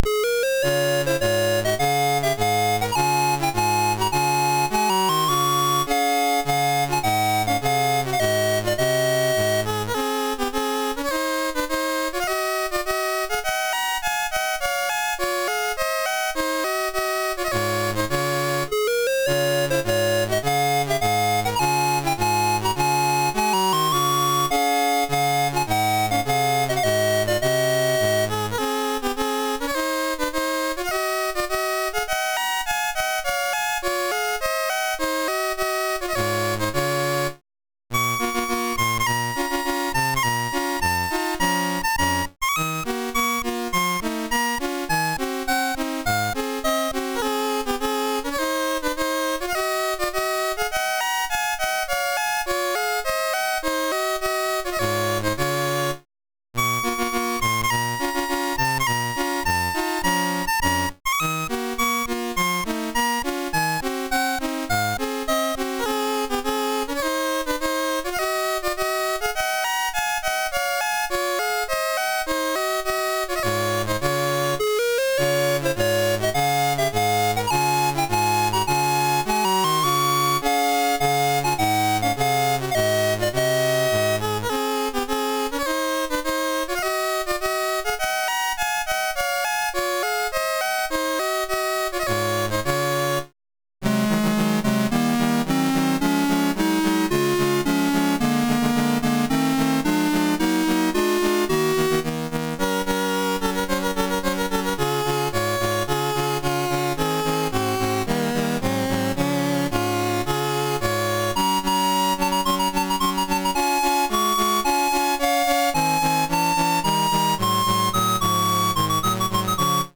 Loadstar 147 home *** CD-ROM | disk | FTP | other *** search / Loadstar 147 / 147.d81 / wengerka.mus ( .mp3 ) < prev Commodore SID Music File | 2022-08-26 | 2KB | 1 channel | 44,100 sample rate | 3 minutes